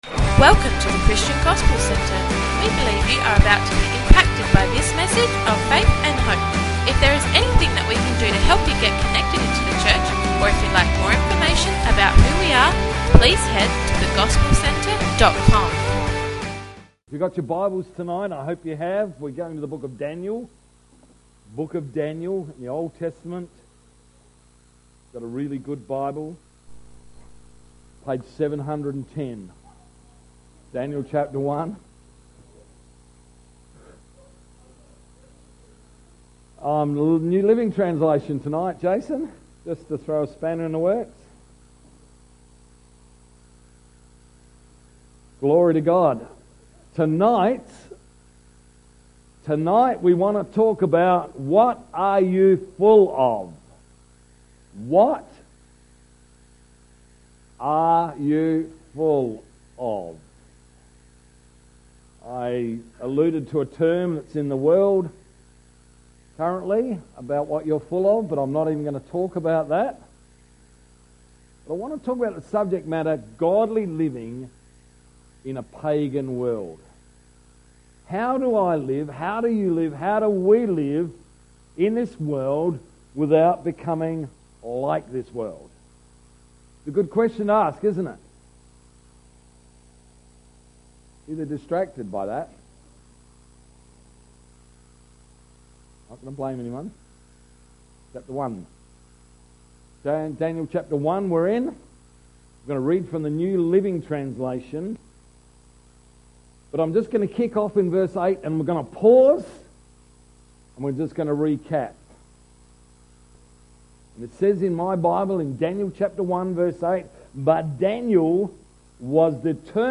21st February 2016 – Evening Service